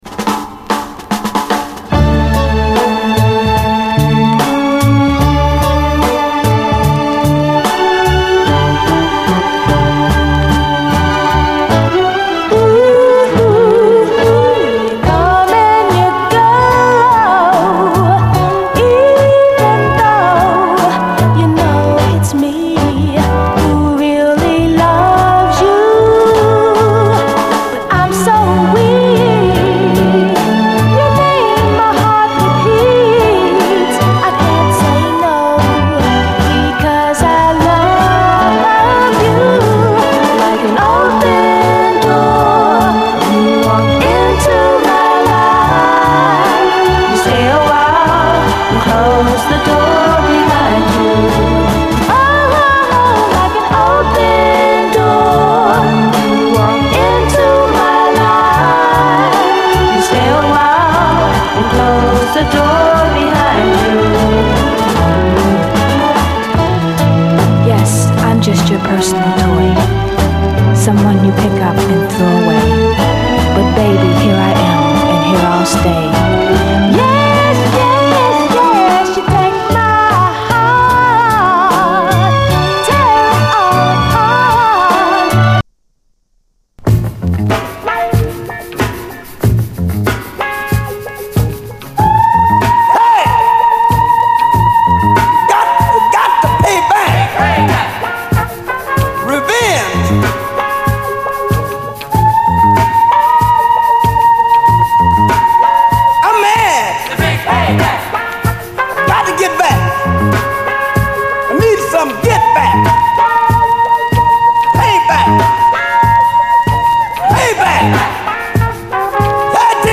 SOUL, 70's～ SOUL, 7INCH